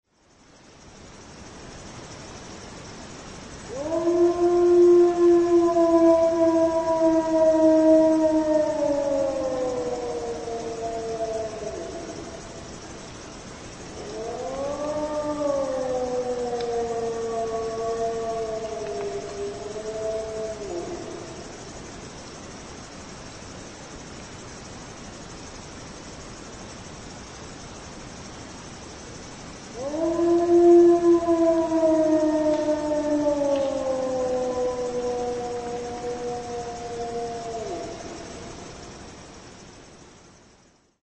Wycie wilka można z łatwością odróżnić od wycia psa. Pies każdo razowo przed wyciem szczeka oraz kończy szczekaniem, wilki nigdy tego nie robią.
wilk3.mp3